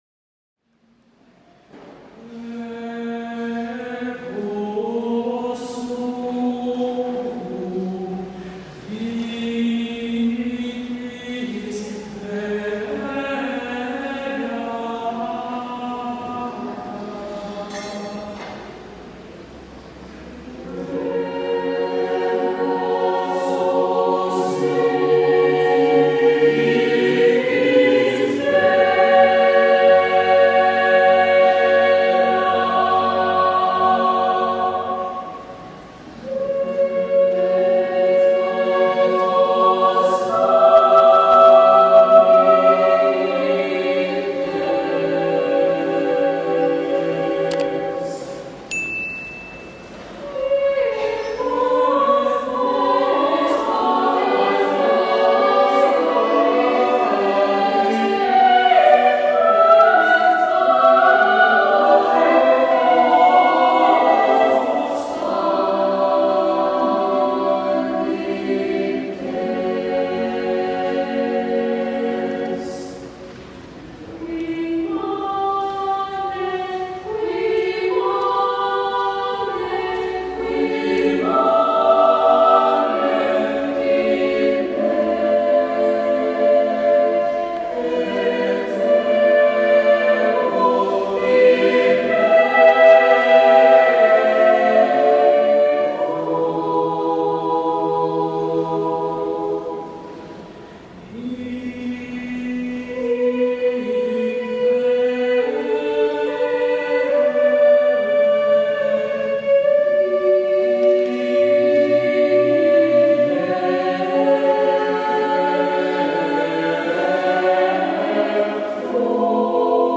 Number of voices: 4vv Voicing: SATB Genre: Sacred, Motet, Communion for Easter 5BC
Language: Latin Instruments: A cappella